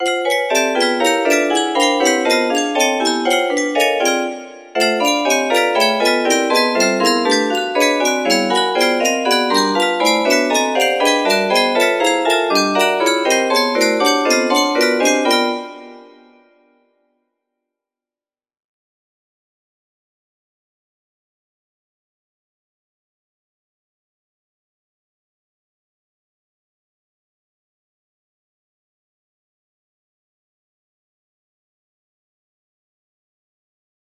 P25 music box melody